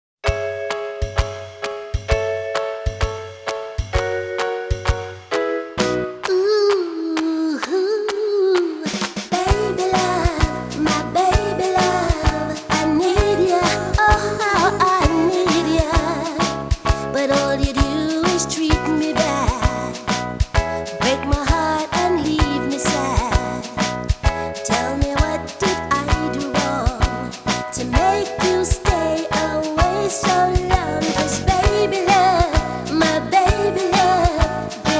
Жанр: Поп музыка / R&B / Соул